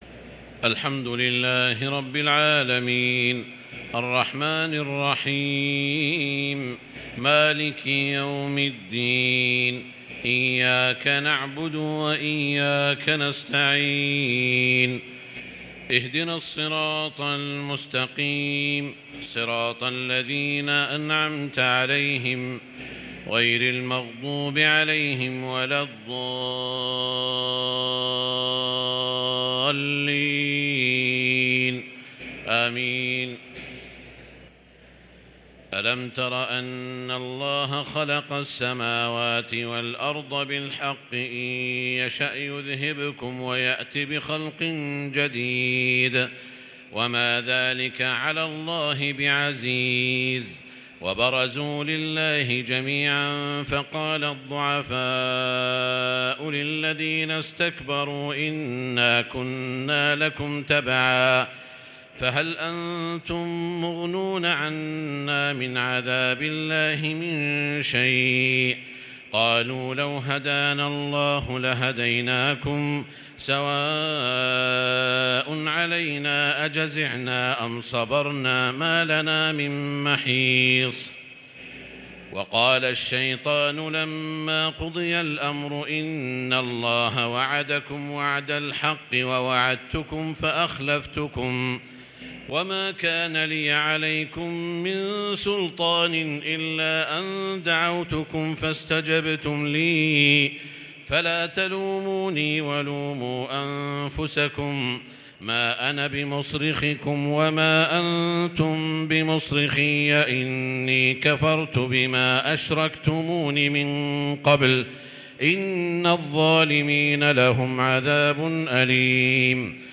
صلاة التراويح ليلة 18 رمضان 1443 للقارئ سعود الشريم - الثلاث التسليمات الأولى صلاة التراويح